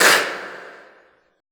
Index of /90_sSampleCDs/Best Service - Extended Classical Choir/Partition I/CONSONANTS